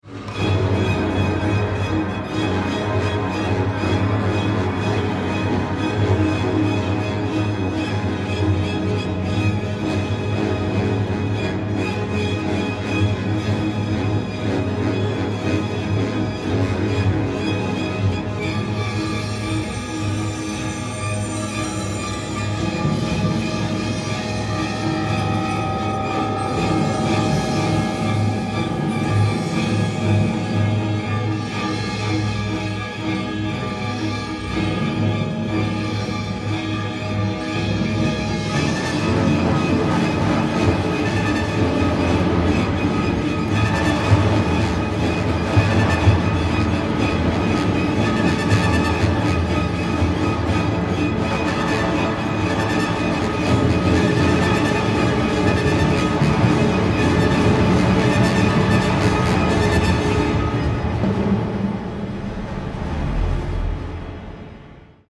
na orkiestrę symfoniczną i taśmę